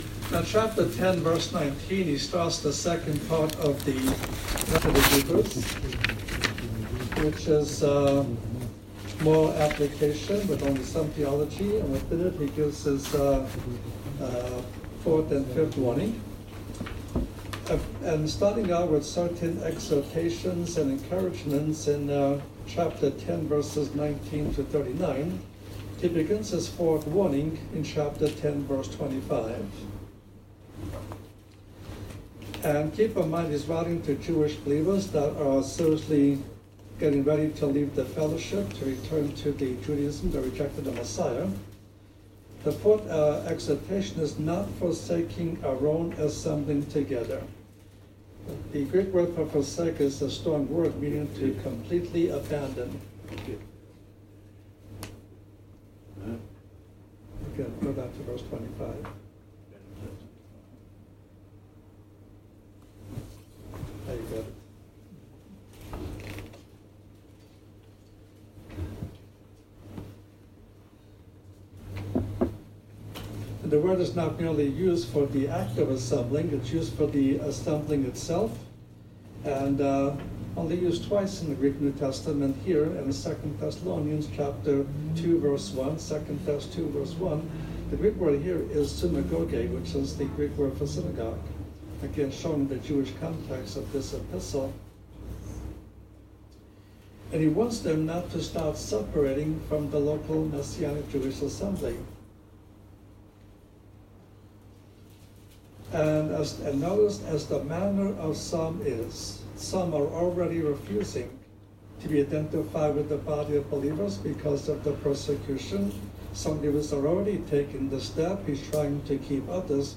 God, Lord God Most High, Bible, Christian, Christianity, Jesus Christ, Jesus, salvation, good news, gospel, messages, sermons